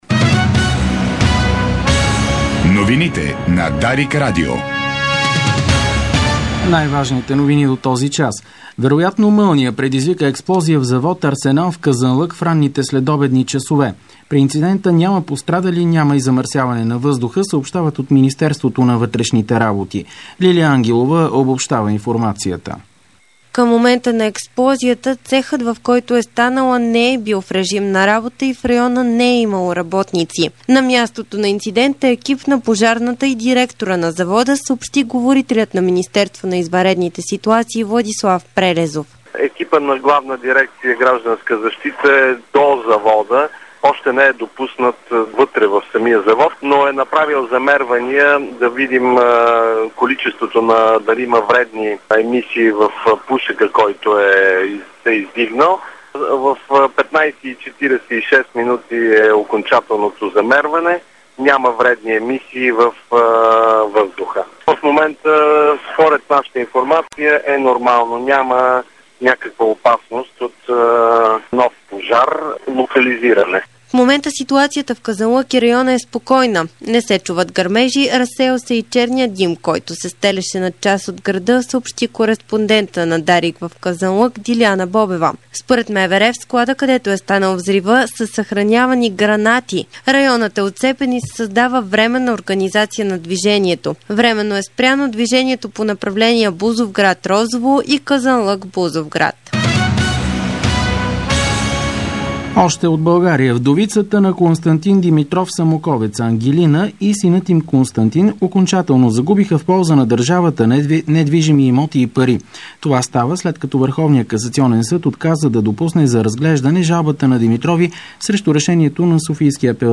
Обзорна информационна емисия - 10.08.2008